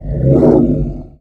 MONSTER_Growl_Medium_17_mono.wav